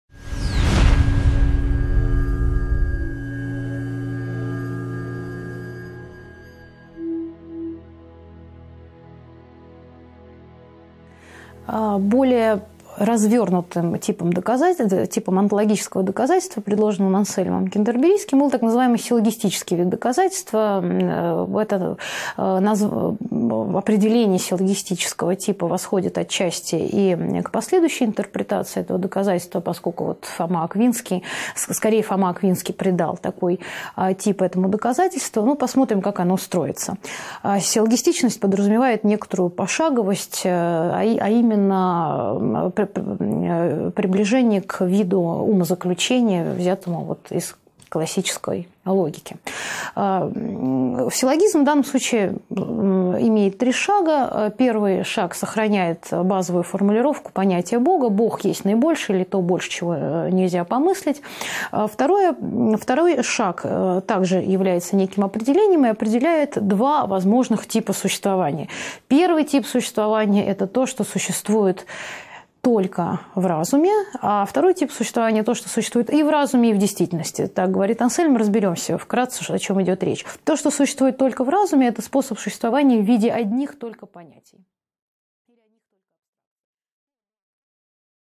Аудиокнига 6.6 Доказательство Ансельма Кентерберийского (онтологическое доказательство) | Библиотека аудиокниг